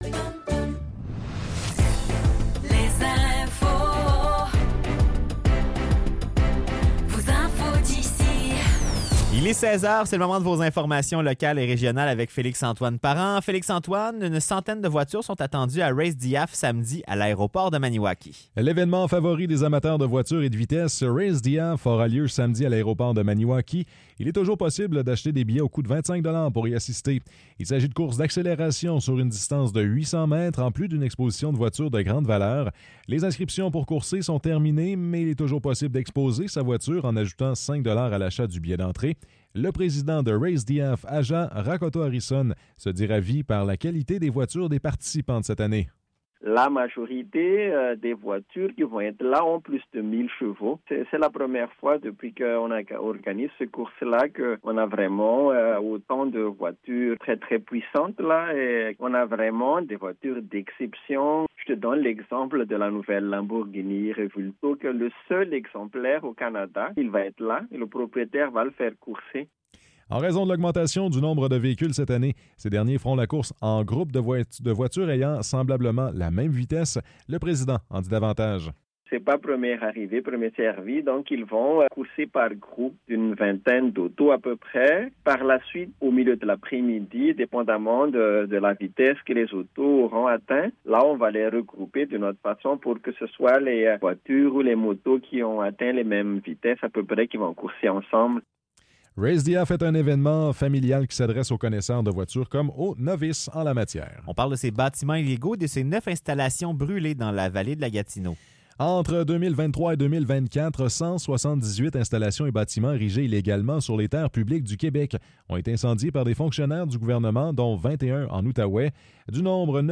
Nouvelles locales - 29 Août 2024 - 16 h